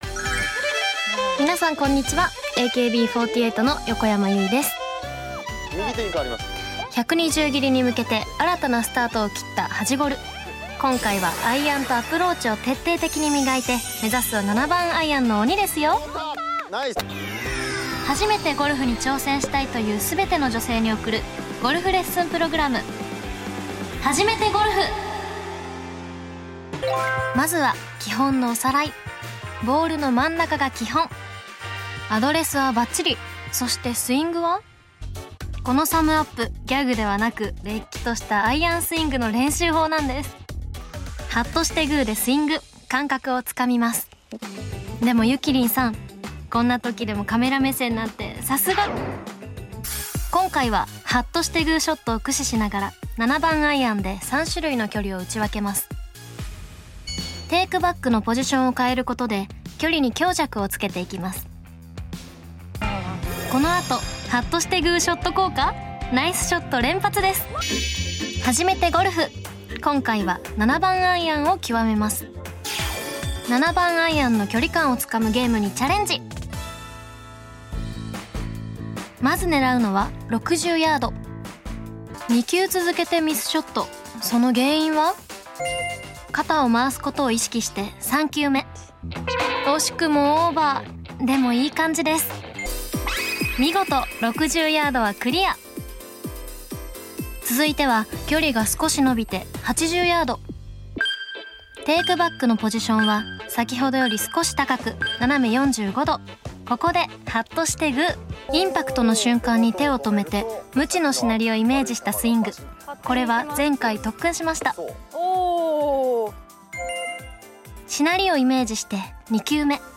171107 골프방송 시즌2 ep03 - 유이 나레이션 CUT
171107 골프방송 시즌2 ep03 - 유이 나레이션 CUT # 유이 목소리만 듣기→ # 영상이랑 같이 플레이어 불러오는 중...